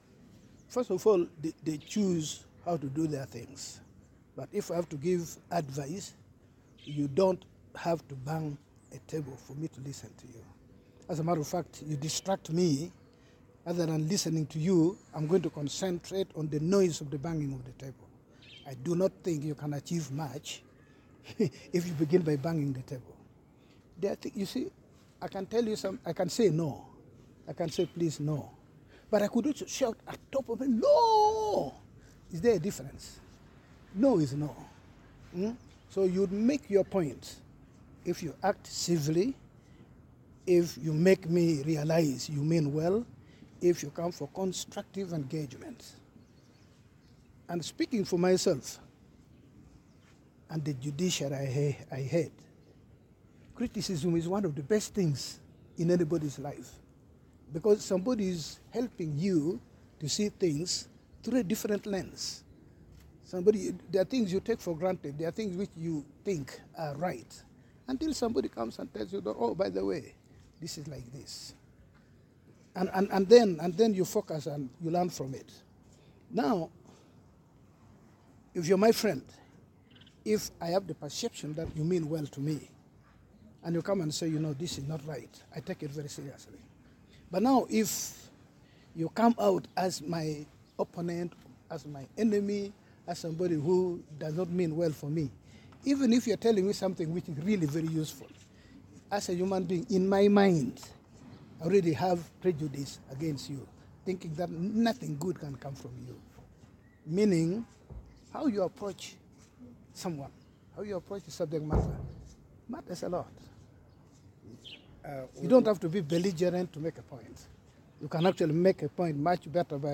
He made these remarks during his opening speech at the four-day annual retreat for Supreme Court and Court of Appeal justices, held at Las Vegas Garden Hotel in Mbarara City.